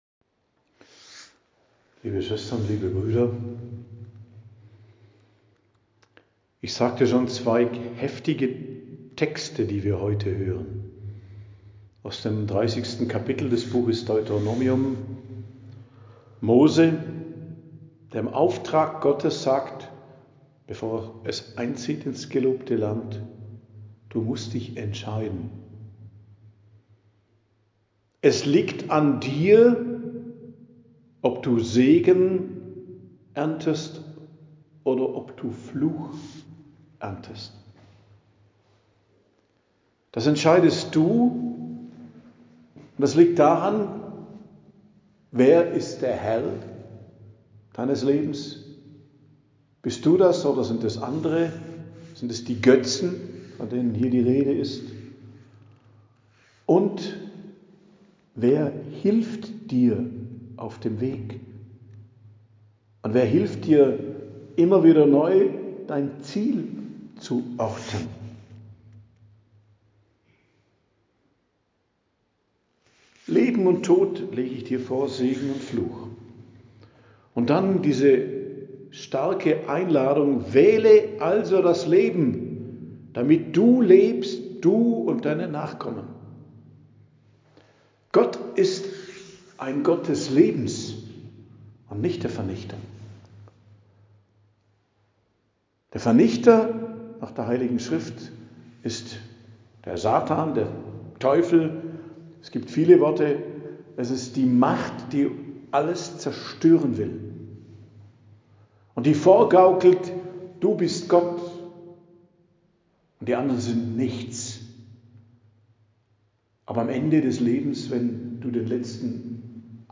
Predigt am Donnerstag nach Aschermittwoch, 6.03.2025 ~ Geistliches Zentrum Kloster Heiligkreuztal Podcast